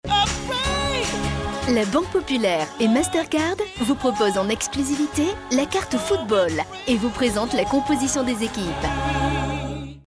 Voix jeune et fraiche mais pouvant être aussi plus mature ou plus sensuelle.
Sprechprobe: Werbung (Muttersprache):